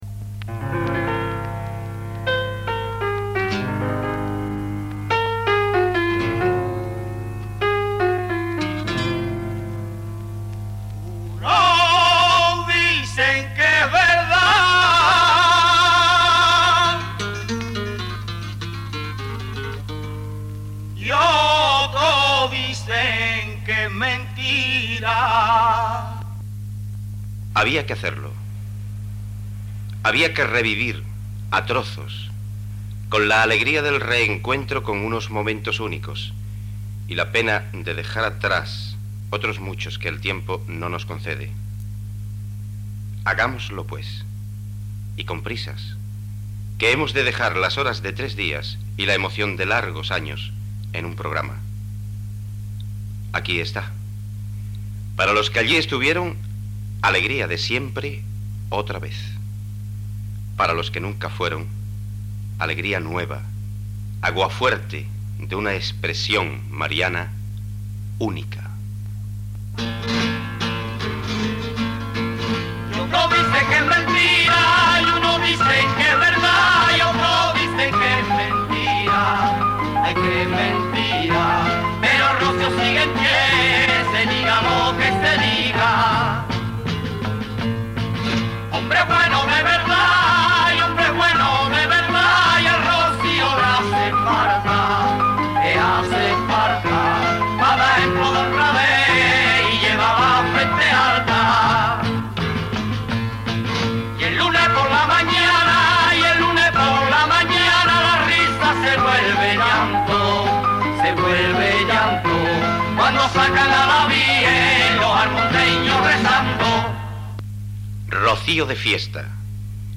Reportatge sobre El Rocío a Almonte (Huelva)